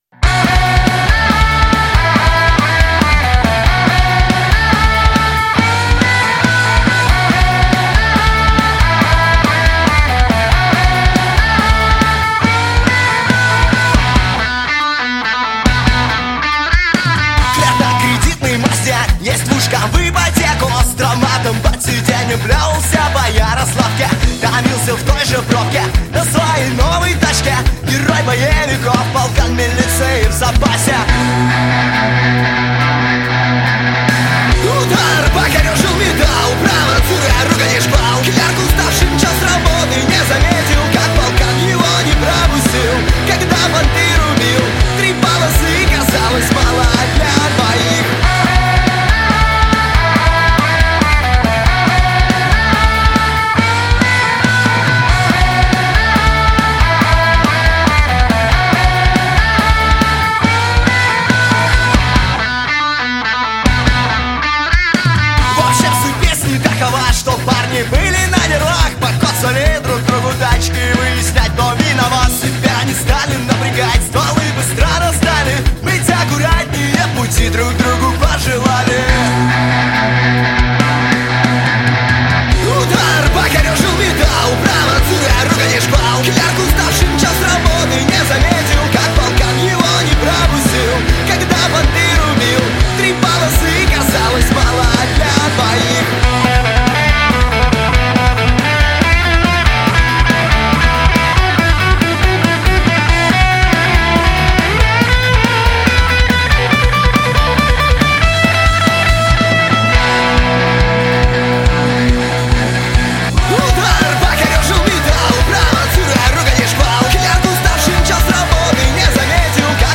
Жанр: Альтернатива / Рок / Для тренировок